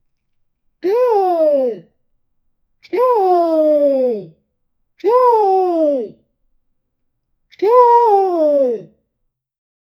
Крик кукушки "нет", несколько раз протяжно, разборчиво чтоб можно было точно понять текст, очень важно чтобы было ощущение как будто кукушка говорит это слово "нет", она отговаривает и предостерегает, важно слышать все буквы слова 0:10 Created Jun 9, 2025 1:13 PM